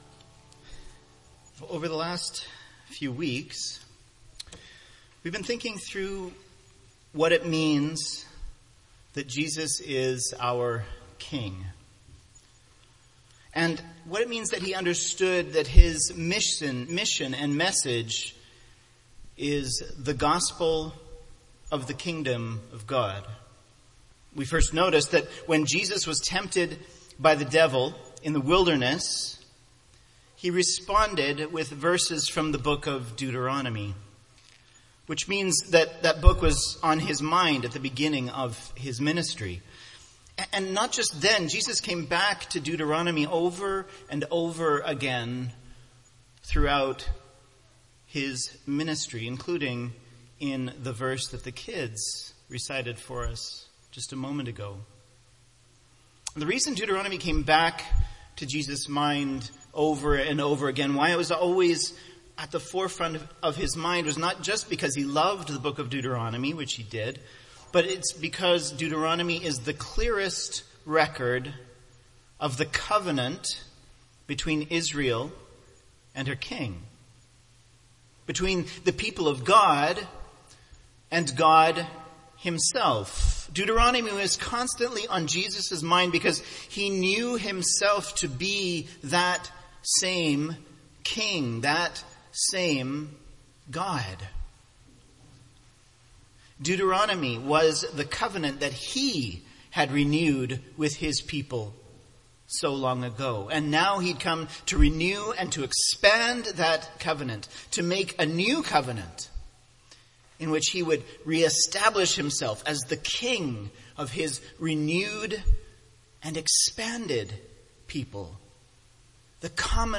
Covenant of Love: “Love Not Far” :Bethesda Sermon Audio